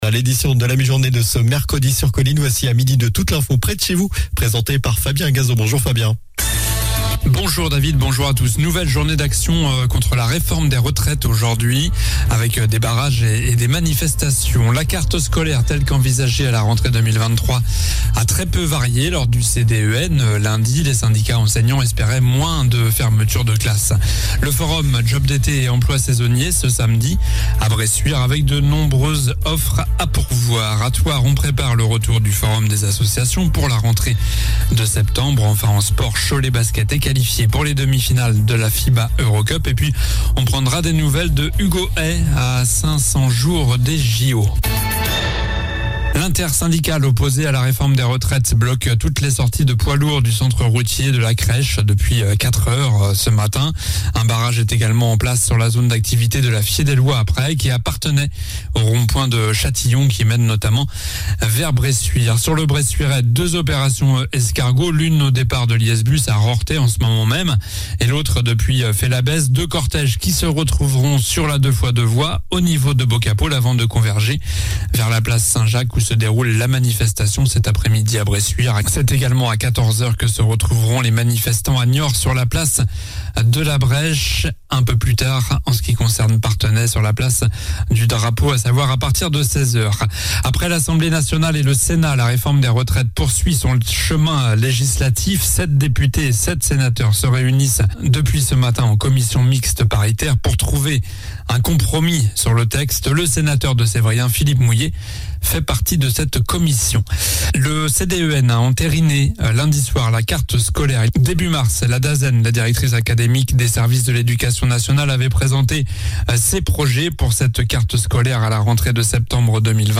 Journal du mercredi 15 mars (midi)